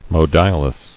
[mo·di·o·lus]